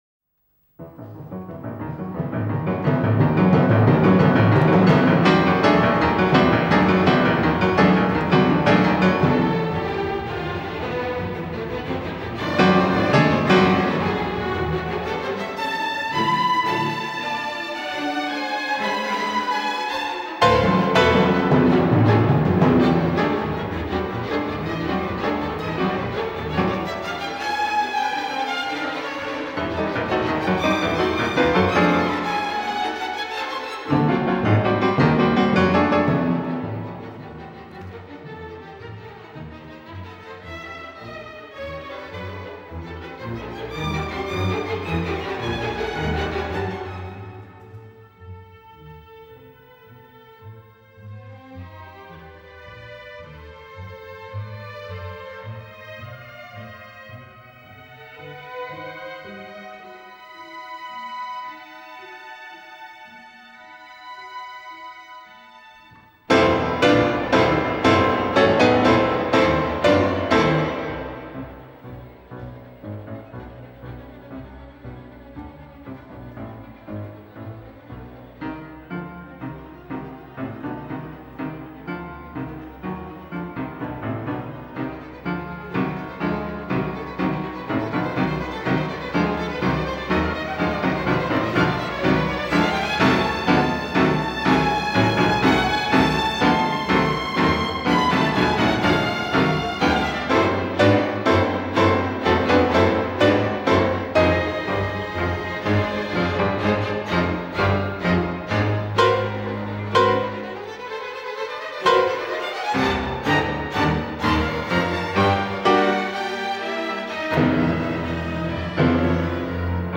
Enerģisks
Simfoniskā mūzika
Mazā ģilde